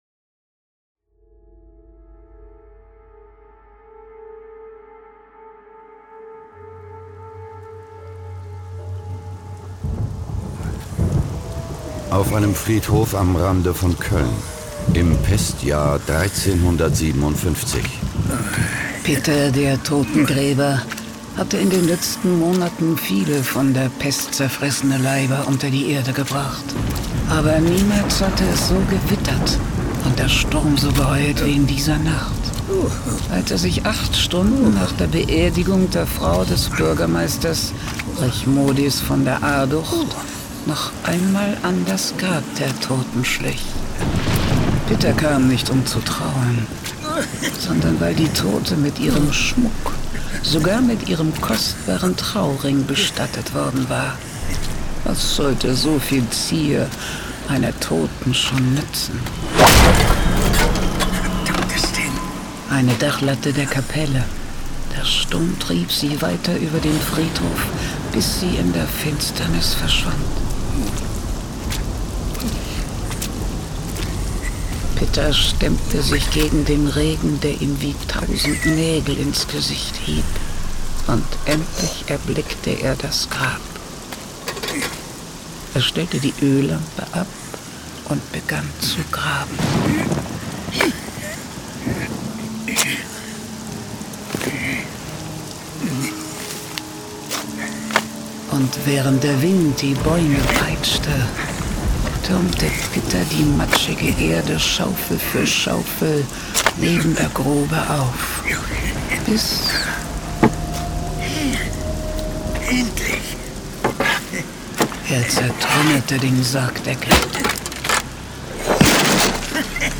John Sinclair - Folge 160 Die Unheimliche vom Schandturm . Hörspiel.